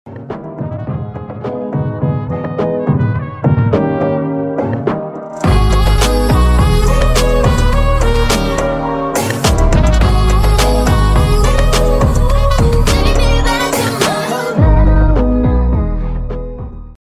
i had to modify the pitch so tik tok wouldnt mute it